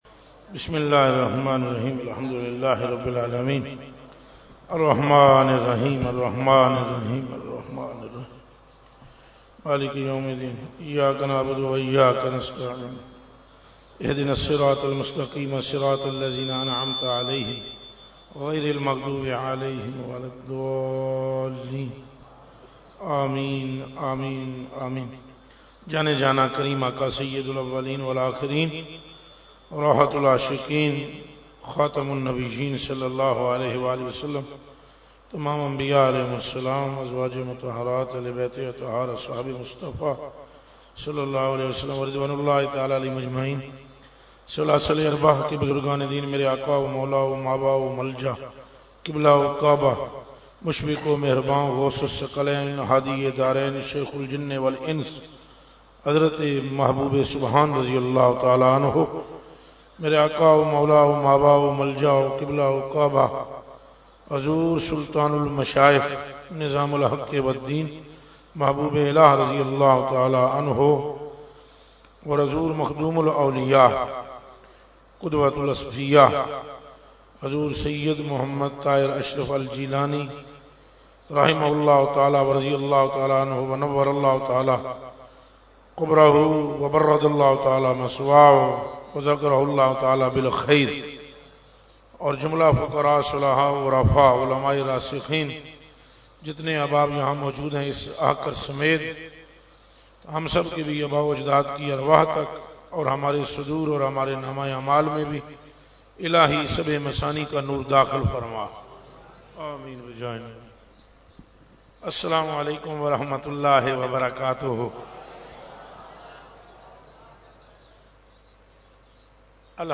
Category : Speech | Language : UrduEvent : Urs Qutbe Rabbani 2018